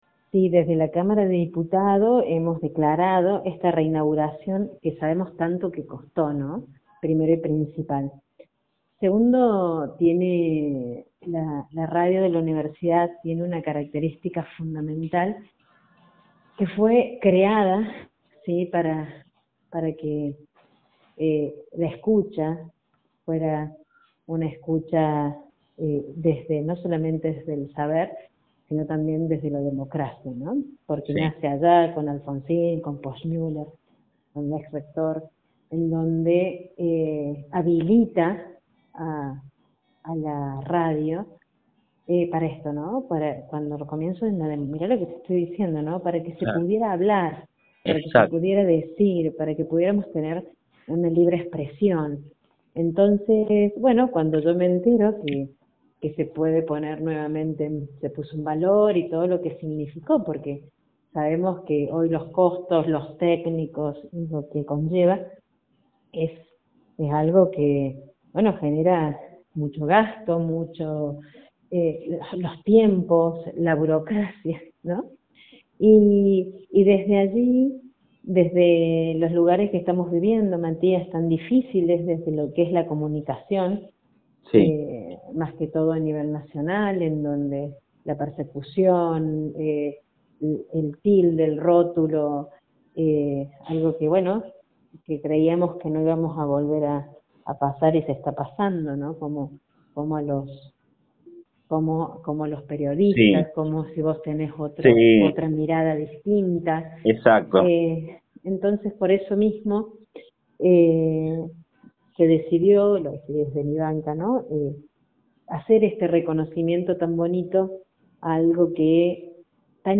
En diálogo con la diputada provincial Ivana Ricca destacó que la distinción fue votada por unanimidad, lo que refleja el respaldo total de la Legislatura al rol de la radio universitaria en la vida cultural, educativa y social de Villa Mercedes y la región.